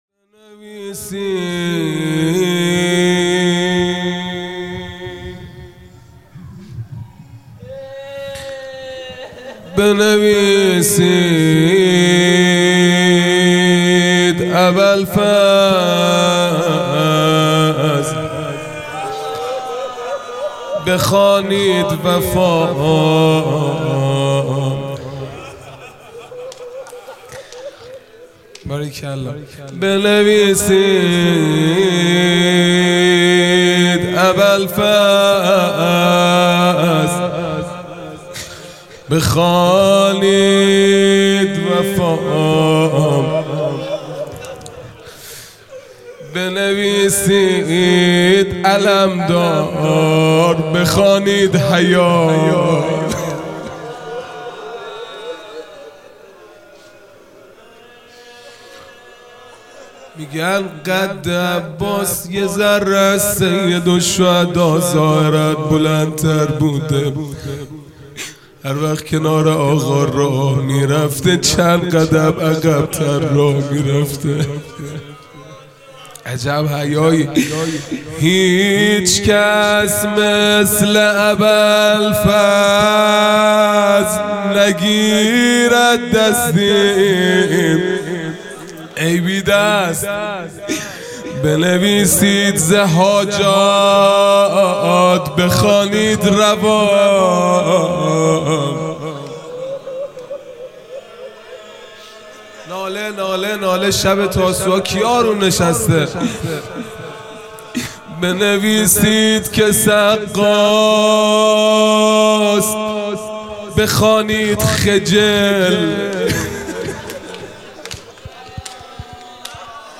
خیمه گاه - هیئت بچه های فاطمه (س) - روضه | بنویسید اباالفضل بخوانید وفا | ۴ مرداد ۱۴۰۲